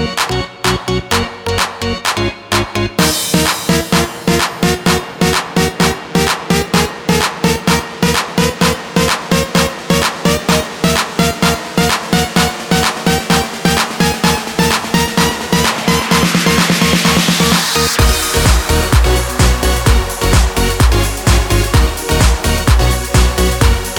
no Backing Vocals R'n'B / Hip Hop 3:35 Buy £1.50